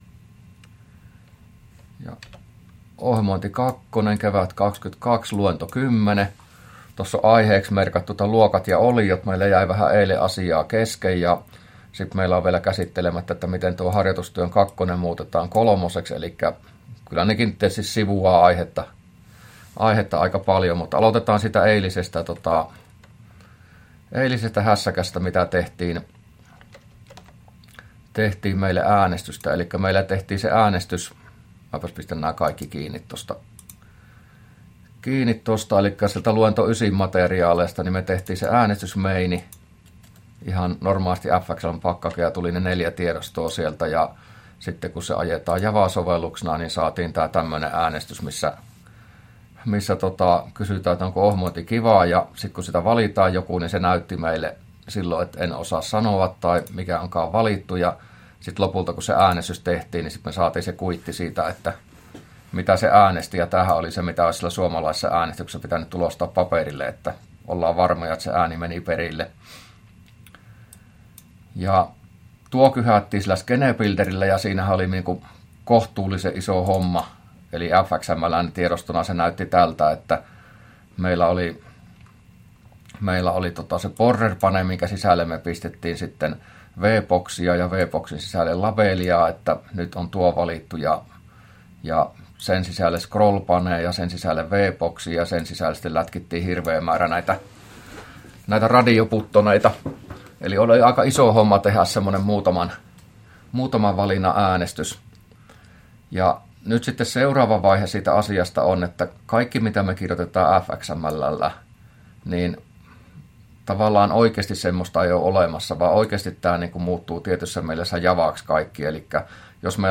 luento10a